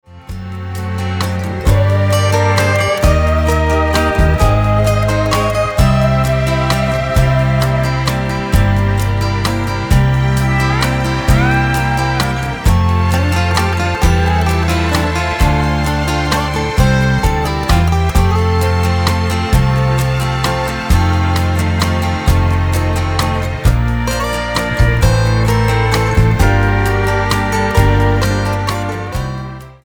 Tonart:F-G mit Chor